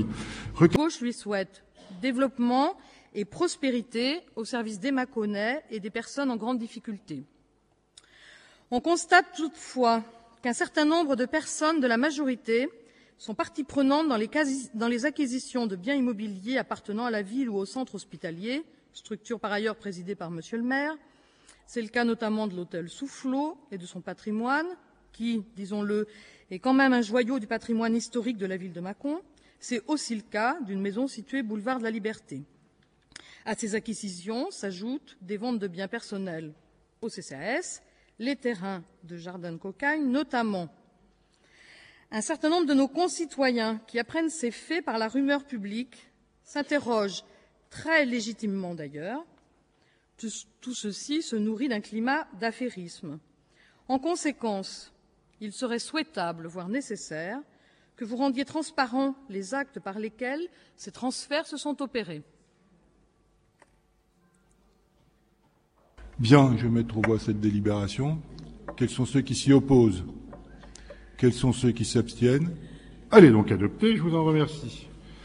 Cliquez sur le lien et Ecoutez les interventions de l’opposition sur le versement d’une subvention pour « retour d’ascenseur » électoral » (